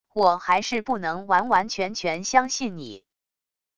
我还是不能完完全全相信你wav音频生成系统WAV Audio Player